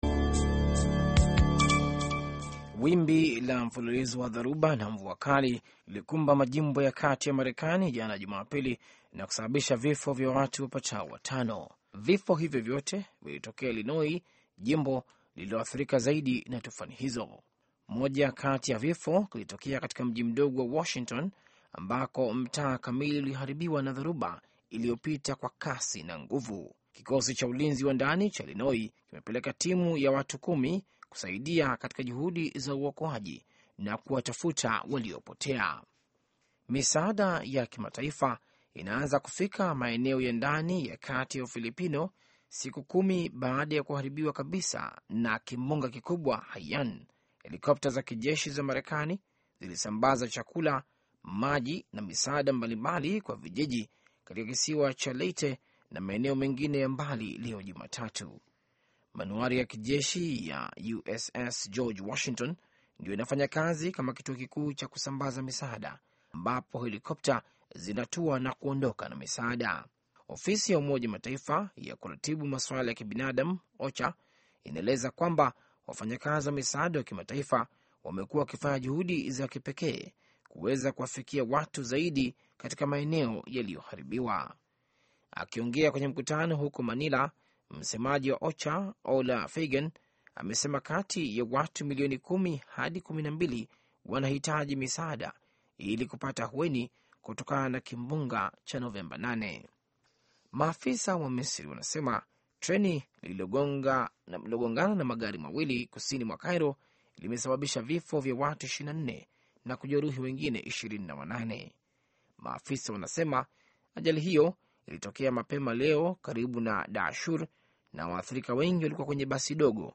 Taarifa ya Habari VOA Swahili - 6:47